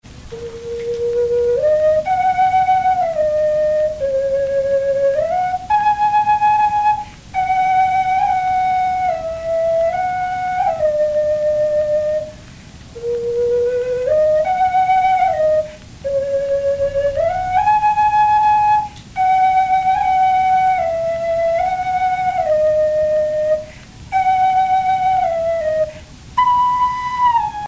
flûte
Pièce musicale inédite